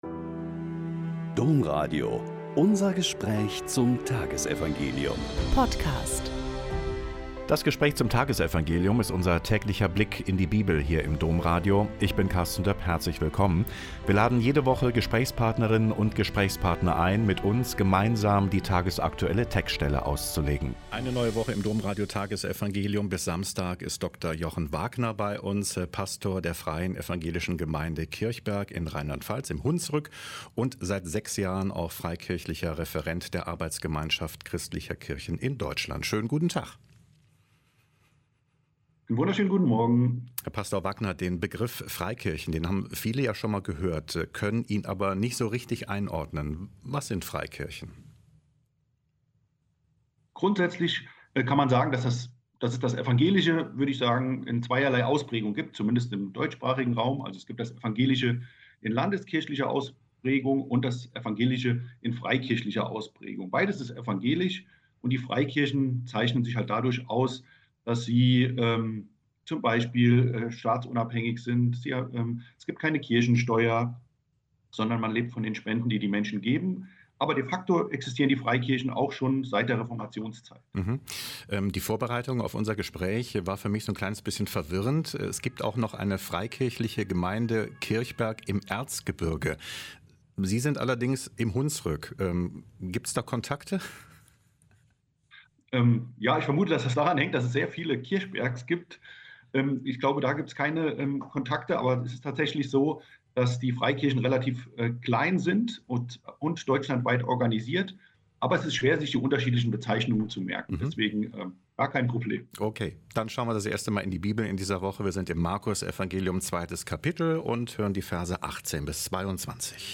Mk 2,18-22 - Gespräch